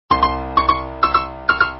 piano nē 275
piano275.mp3